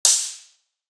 Crashes & Cymbals
Top Sizzle.wav